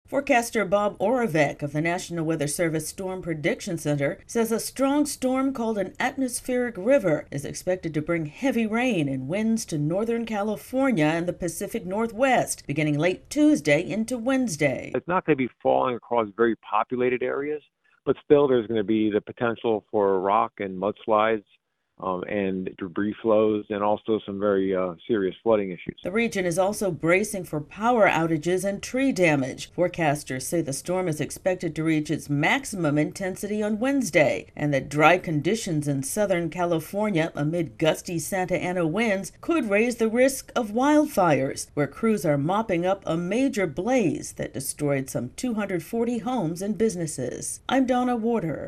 Northern California and the Pacific Northwest are getting ready for what's likely to be the strongest storm of the season so far. AP correspondent